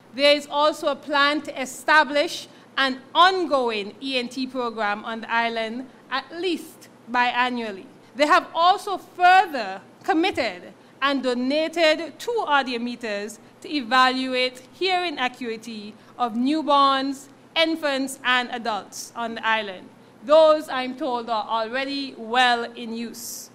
During her maiden presentation in the Nevis Island Assembly, local Minister with responsibility for Health and Gender Affairs, Hon. Jahnel Nisbett, reported on a number of initiatives that the Ministry embarked upon in 2022 and elaborated on the plans for 2023.
Nevis’ Minister of Health and Gender Affairs, Hon. Jahnel Nisbett.